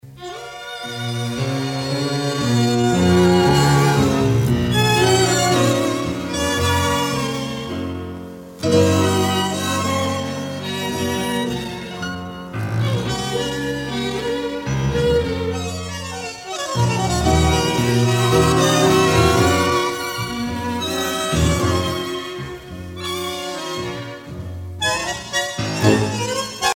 danse : tango (Argentine, Uruguay)